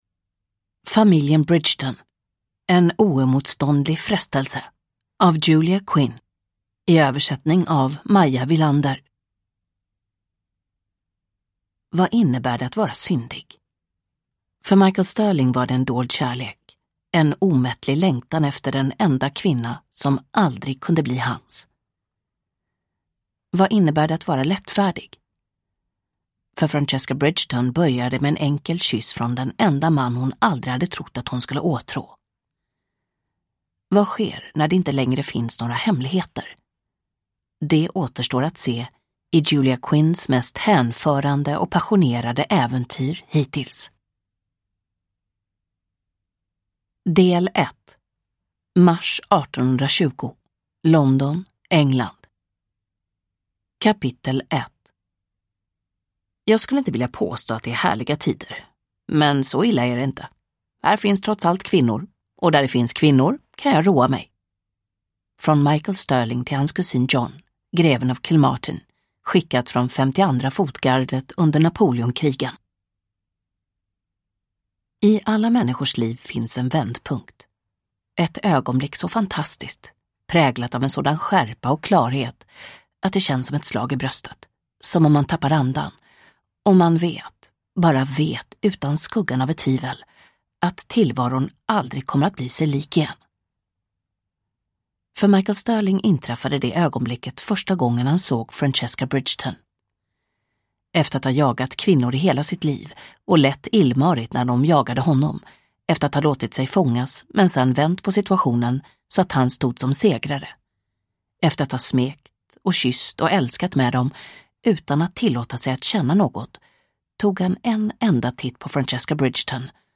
En oemotståndlig frestelse (ljudbok) av Julia Quinn